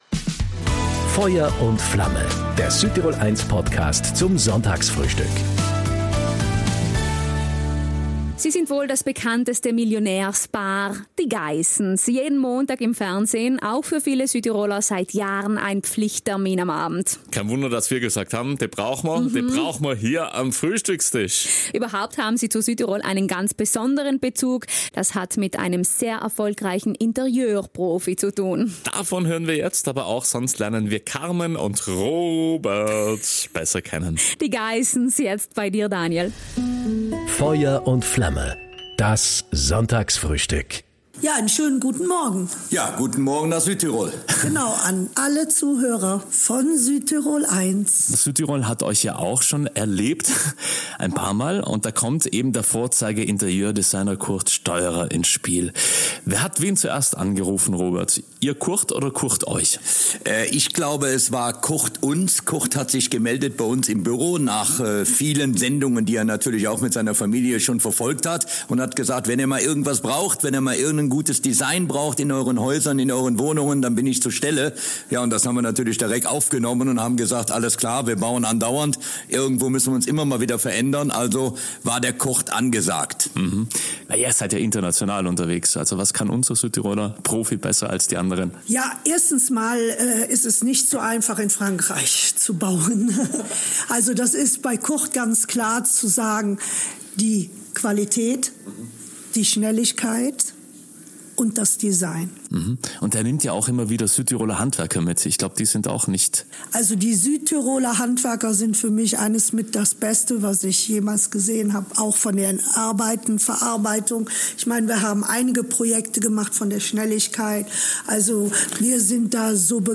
In diesem Sonntagsfrühstück sind mit Carmen und Robert Geiss zwei der bekanntesten Reality-TV-Gesichter im deutschsprachigen Raum zu Gast. Das Unternehmer- und Millionärs-Ehepaar spricht offen über seinen Alltag, seine Verbindung zu Südtirol und seine internationale Karriere.